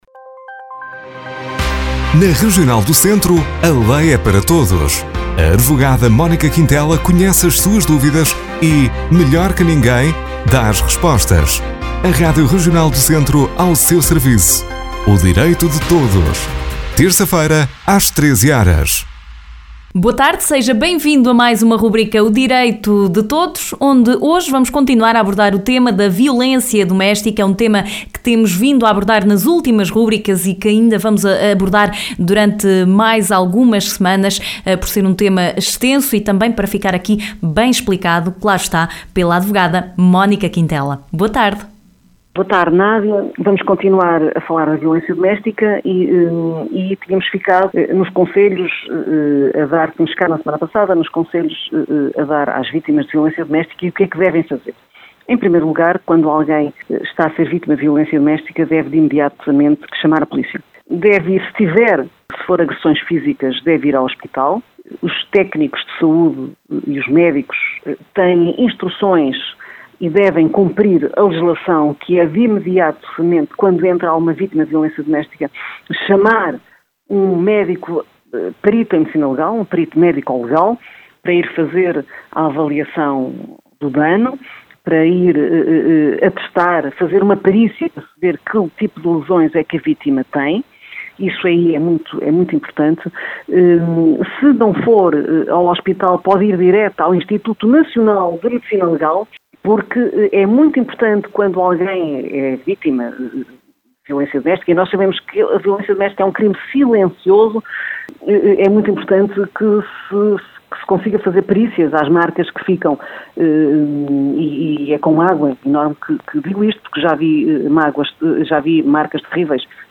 Hoje a advogada Mónica Quintela continua a falar sobre o tema ‘violência doméstica’.